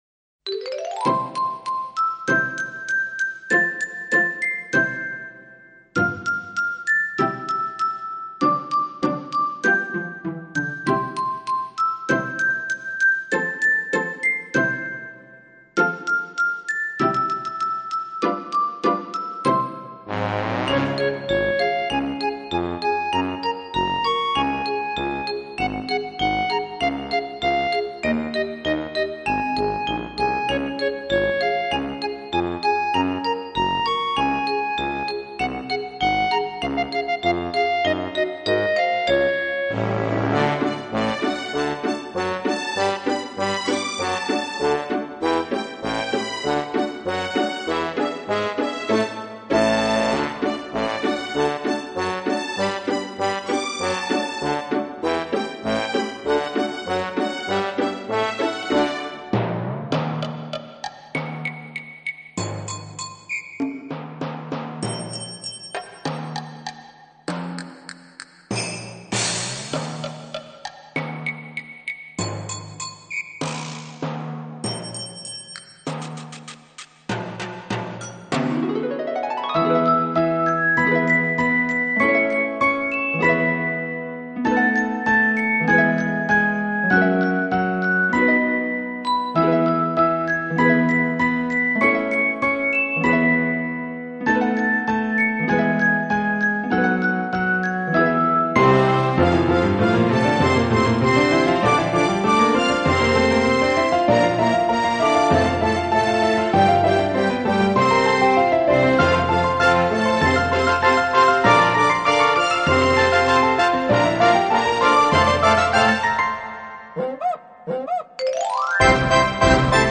在蘭陽平原的稻香迴廊中，聽見純淨的天使聲音穿越畝畝田地.
這是一張童趣十足、音樂性佳、選曲多元化的兒童專輯，
豐富、活潑的音樂內容絕對適合親子共同聆聽！